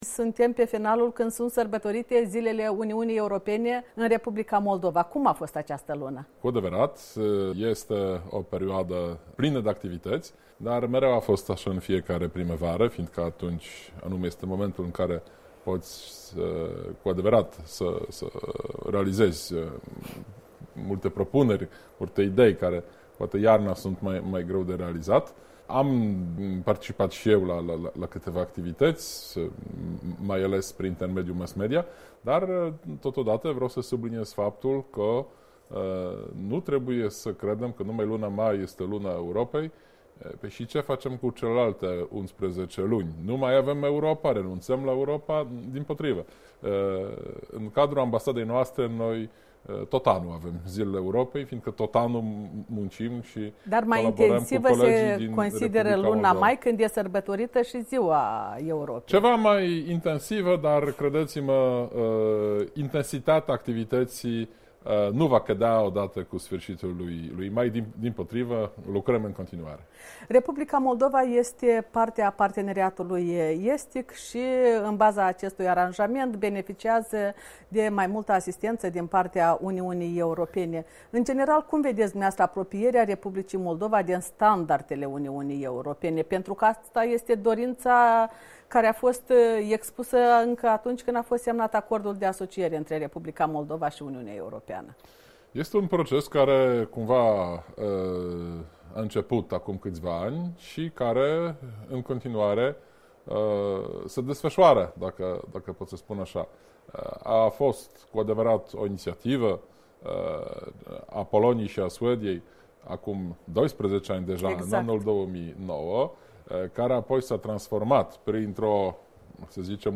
Interviu cu ambasadorul Poloniei, Bartłomiej Zdaniuk